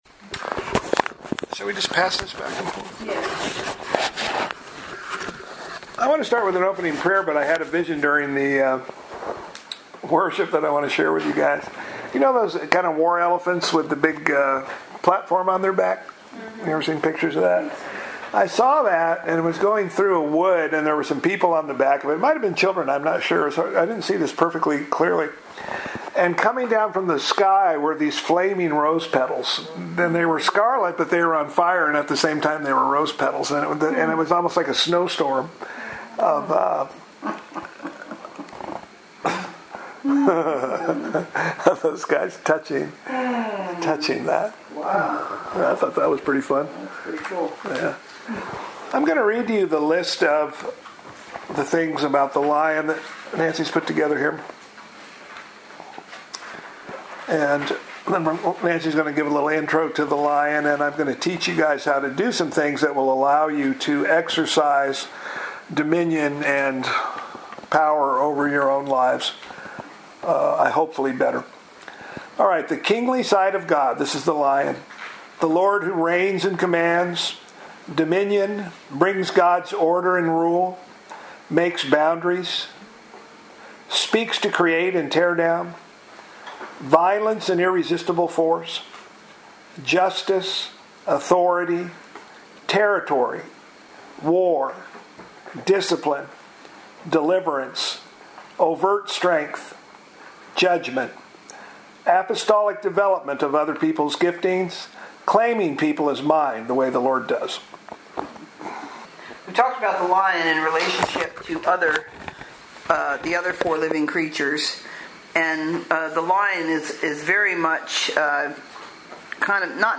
Lesson 1